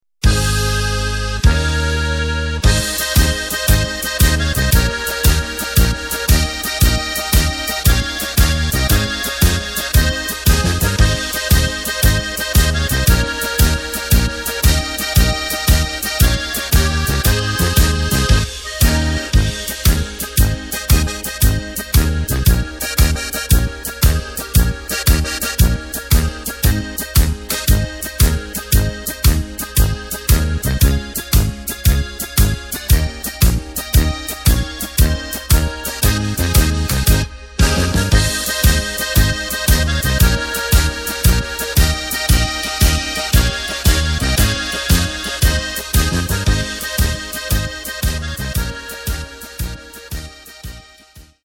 Takt:          2/4
Tempo:         100.00
Tonart:            Ab
Playback mp3 mit Drums und Lyrics